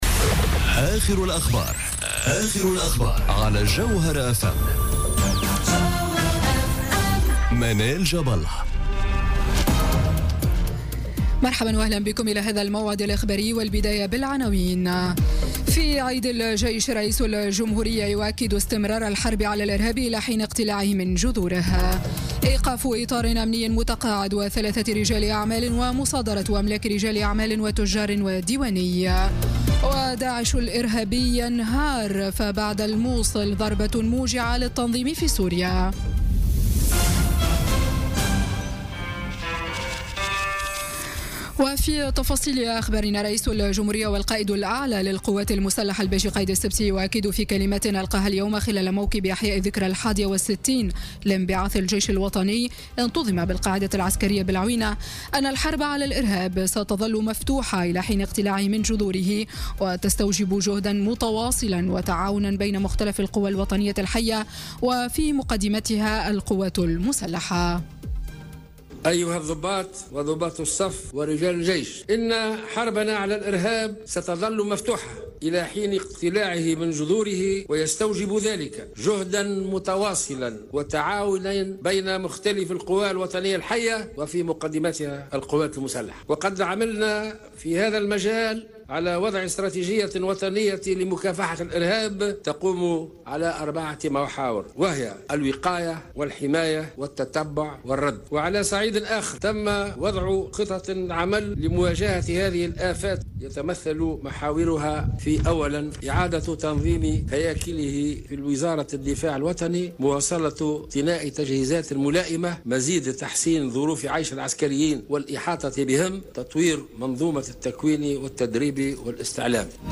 نشرة أخبار السابعة مساء ليوم الجمعة 30 جوان 2017